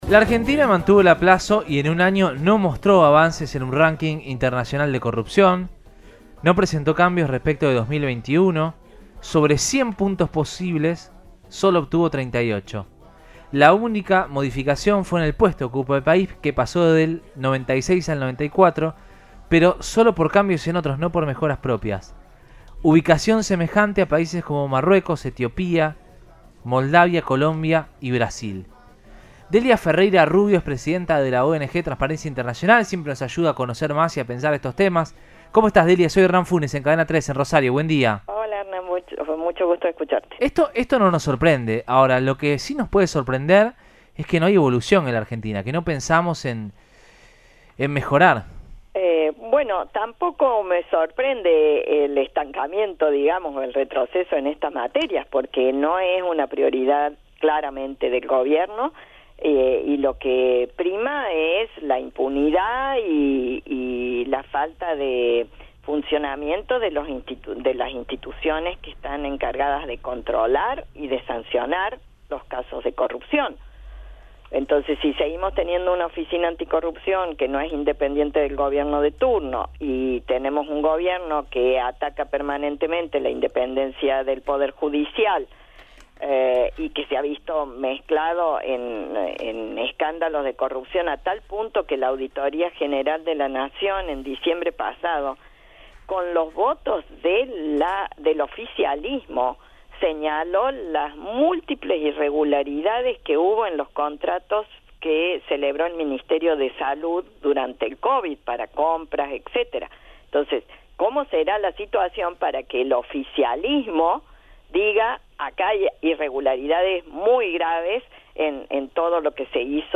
Delia Ferreira Rubio, presidenta de Transparencia Internacional brindó su mirada sobre este ranking en diálogo con Siempre Juntos de Cadena 3 Rosario.